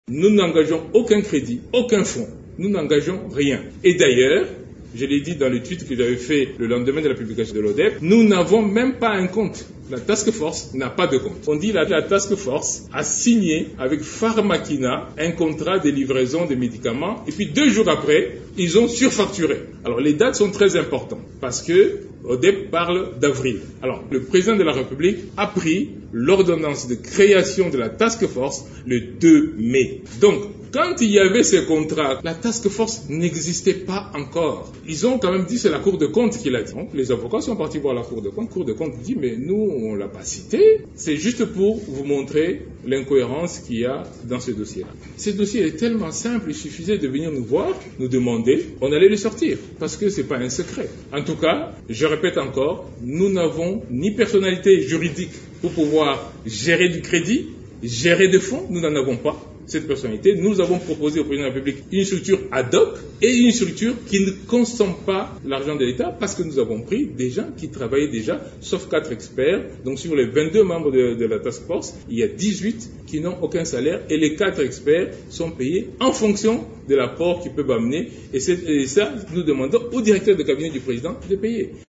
Dans un point de presse, le coordonnateur de la task force COVID-19 de la Présidence de la République, Dr Roger Kamba a précisé que cette structure ne dispose d'aucune prérogative ni d'un statut susceptible d'abuser ou d'influencer la gestion des fonds destinés à la lutte contre la COVID-19.